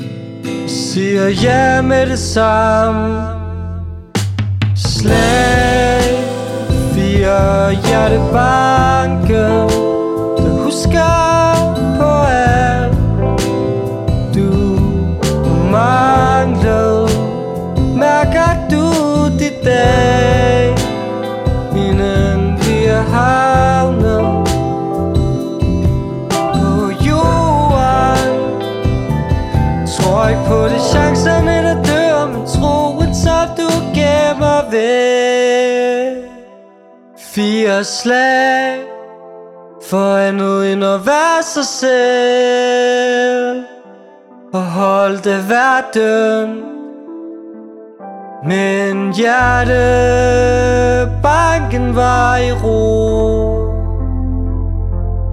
• Indie
• Pop